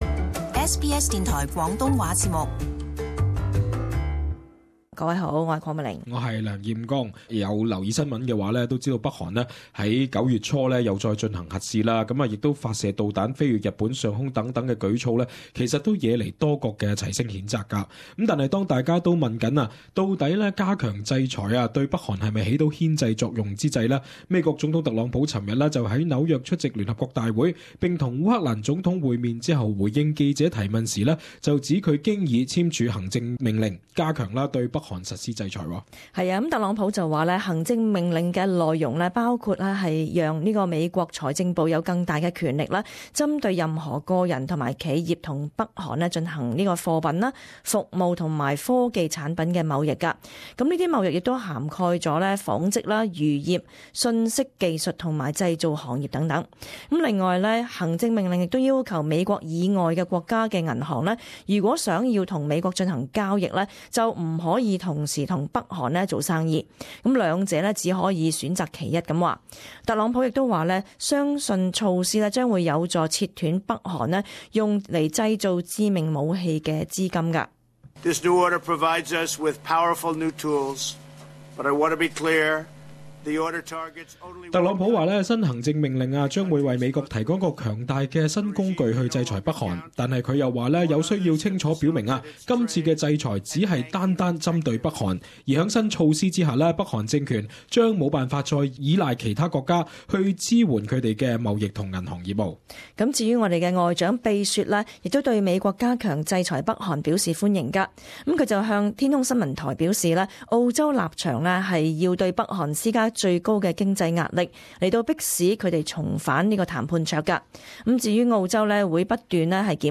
【時事報導】美國宣布加強制裁北韓新措施